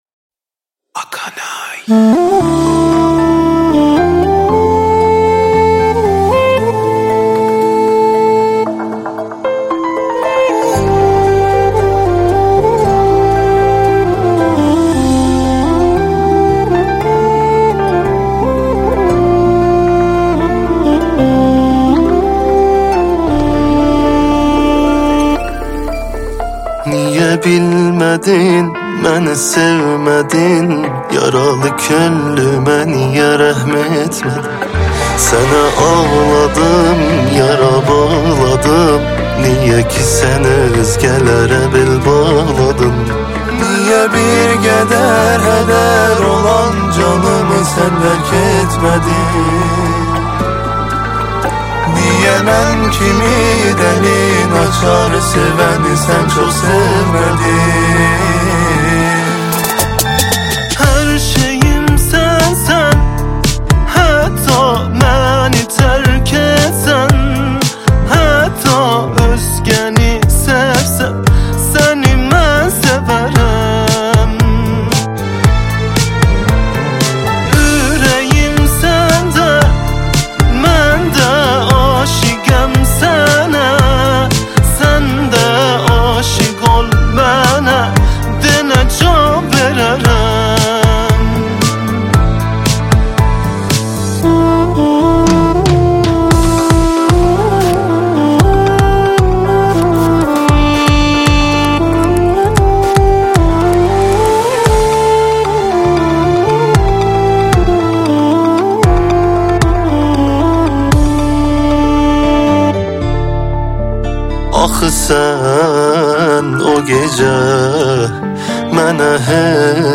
آهنگ غمگین ترکی